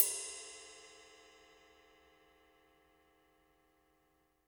Index of /90_sSampleCDs/Roland - Rhythm Section/CYM_Rides 1/CYM_Ride menu